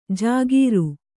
♪ jāgīru